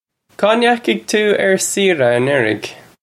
Kaw nyahig too urr seera anurrig?
This is an approximate phonetic pronunciation of the phrase.